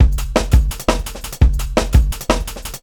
Drum N Bass 1.wav